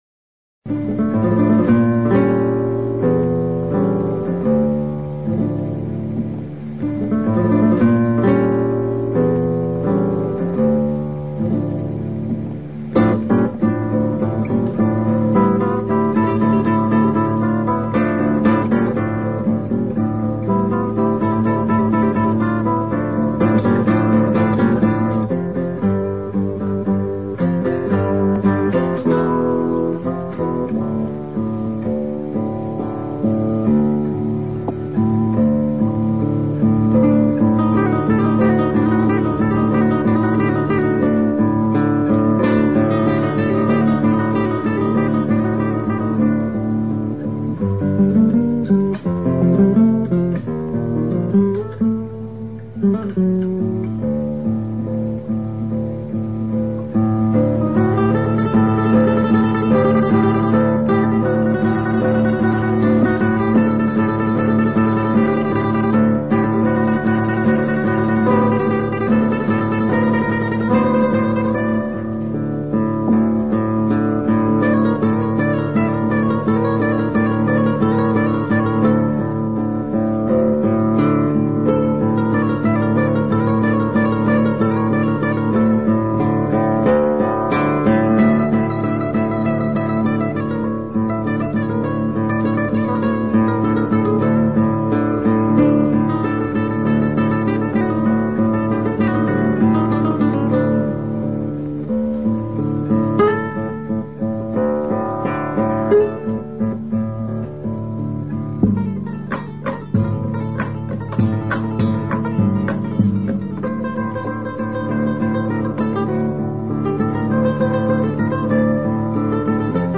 Kresge Little Theater MIT Cambridge, Massachusetts USA